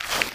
STEPS Newspaper, Walk 01.wav